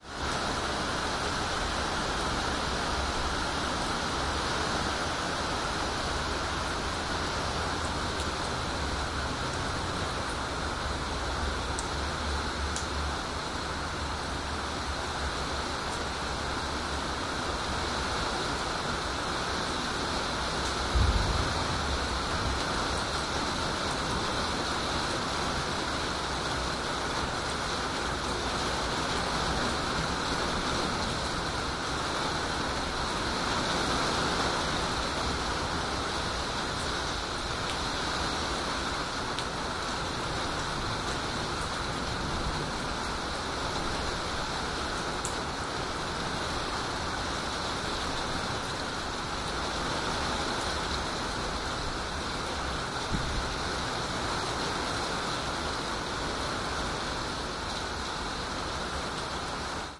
水 " 雨水介质
描述：中等热带雨。哥斯达黎加圣何塞。 设备：Tascam DR100 mkii，Peluso CEMC6（心形帽），ORTF。 原始录音 96kHz / 24bit转换为48kHz / 24位
Tag: 自然 现场录音